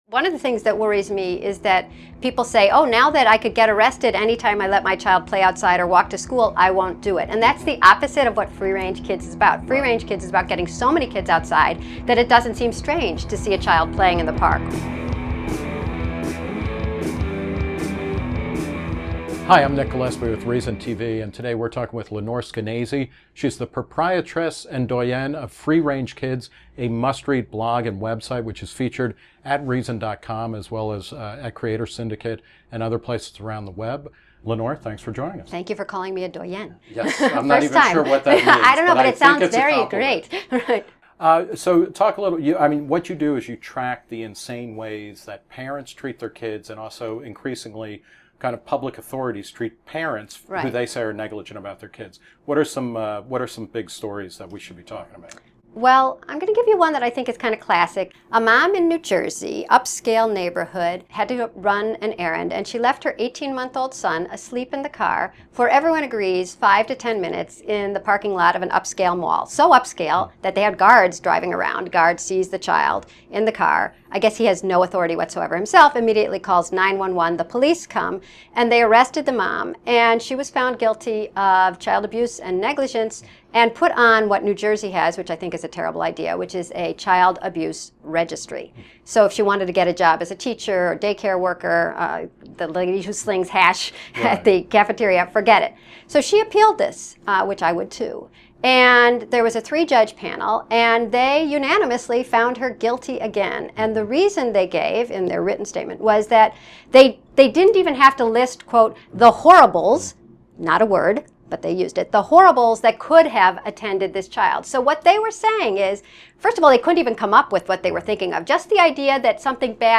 In a conversation with Reason TV's Nick Gillespie , Skenazy debunks the myth that children left unattended in a car for a few minutes are in mortal danger, decries "busybodies" who feel a civic duty to call the police when they see an unaccompanied child, and offers helpful tools for terrified parents on how to let their kids enjoy more freedom to explore the world.